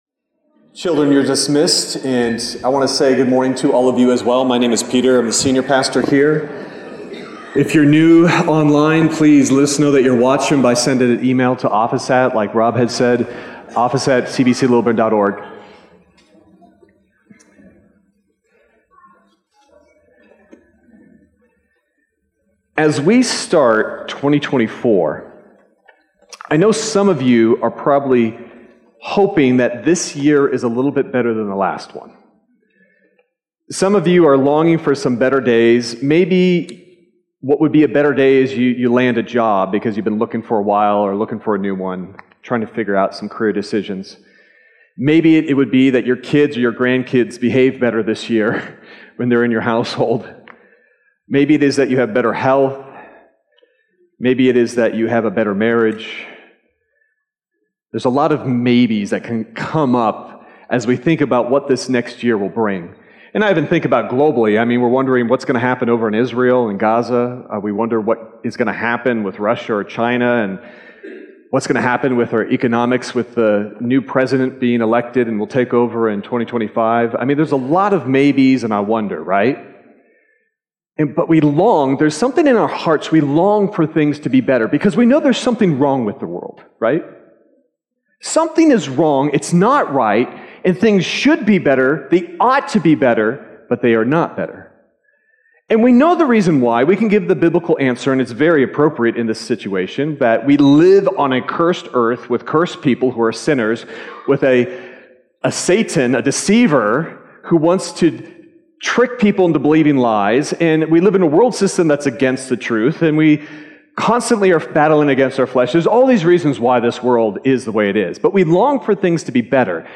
Sermon Detail
January_7th_Sermon_Audio.mp3